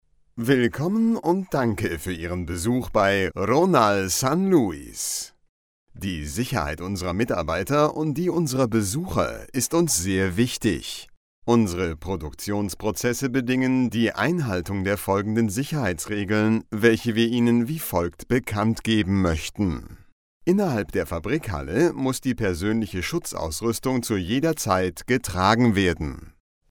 locutor alemán.